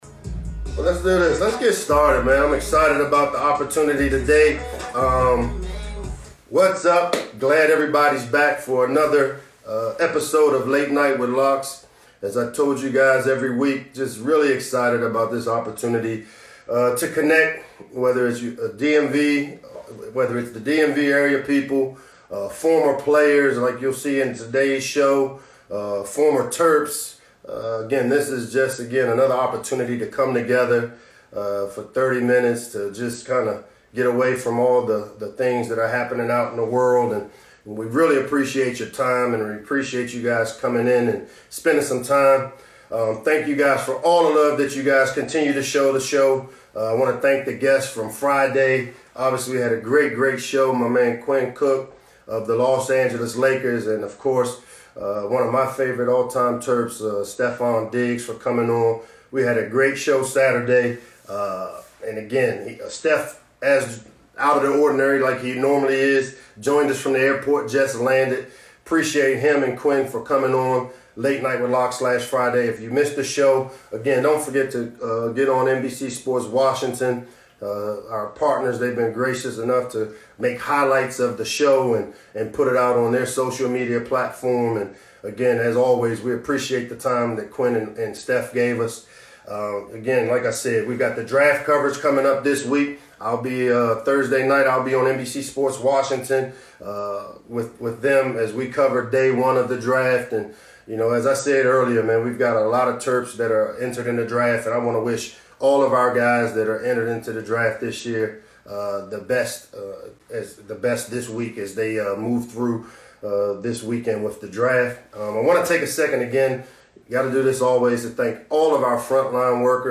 April 23, 2020 Late Night with Locks is an Instagram live show hosted by head football coach Michael Locksley every Tuesday and Friday evening at 7 p.m. This show featured NFL draft hopefuls Jerry Jeudy, Henry Ruggs III & Jalen Hurts.